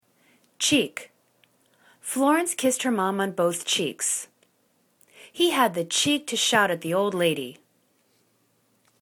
cheek    /che:k/    n